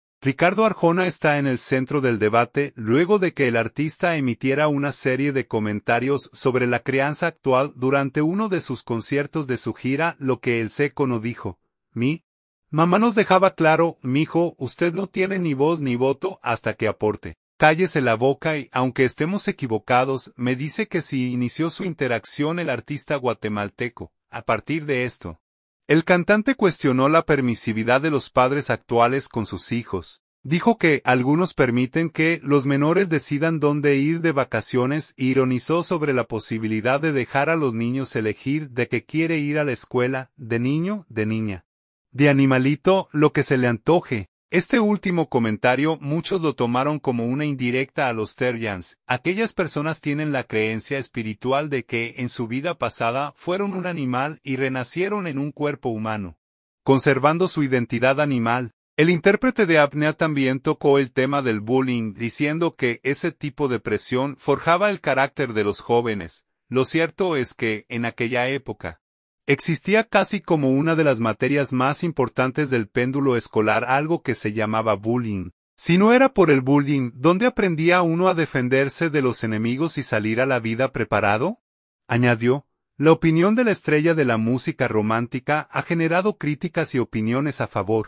Ricardo Arjona está en el centro del debate luego de que el artista emitiera una serie de comentarios sobre la crianza actual durante uno de sus conciertos de su gira “Lo que el Seco no dijo”.